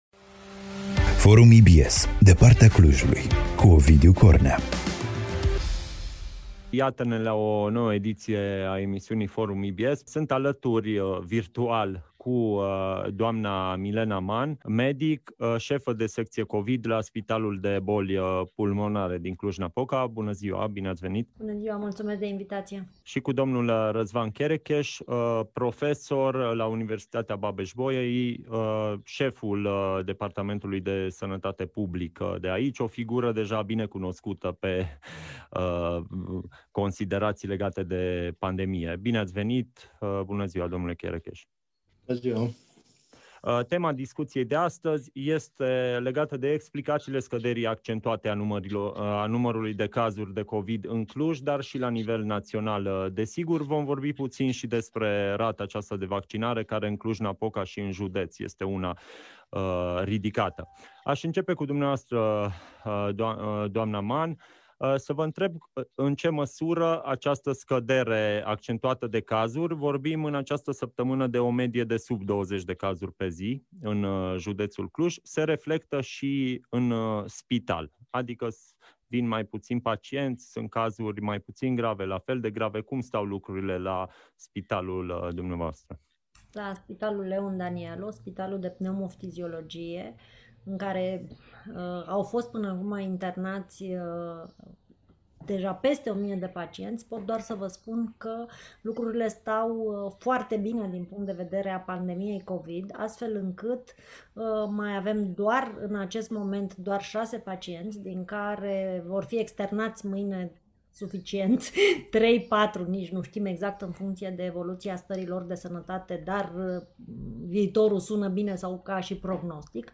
Acestea a fost contextul discuţiei, transmisă LIVE, în format Zoom, pe pagina de Facebook a EBS Radio.